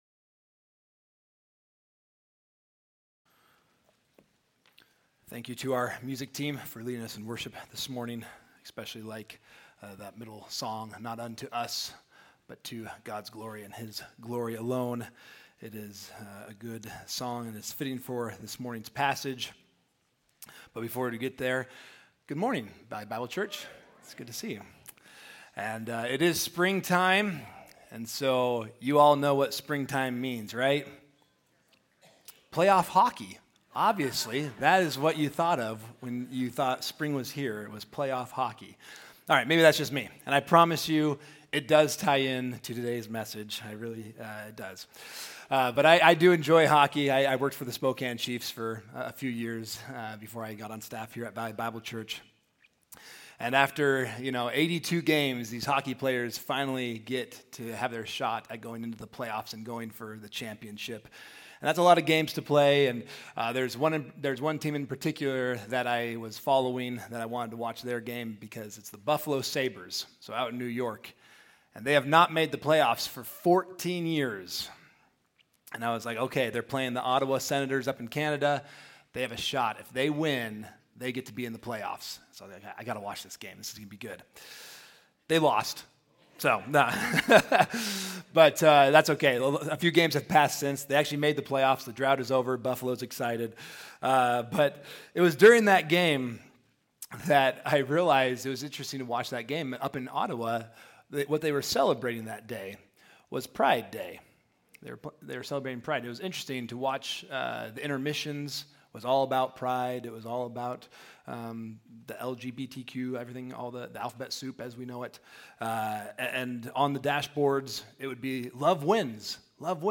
April 12’s Sunday service livestream, bulletin/sermon notes, the online Connection Card, and playlists of Sunday’s music (Spotify and YouTube).